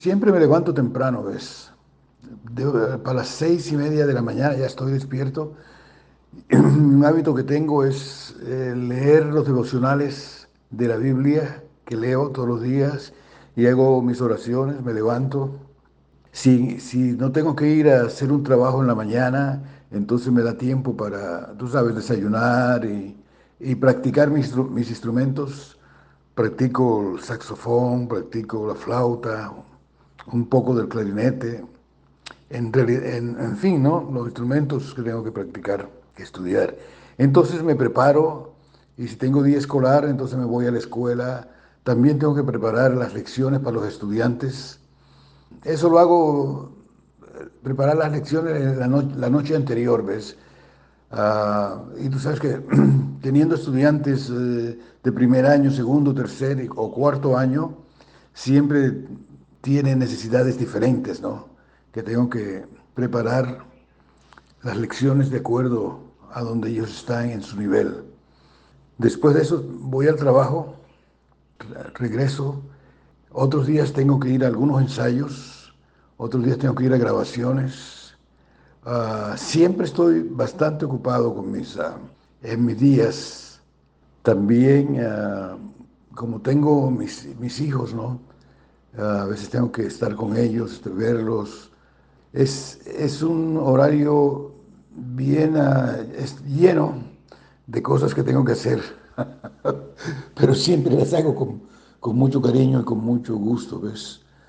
Entrevista personal vía WhatsApp, junio 17 de 2023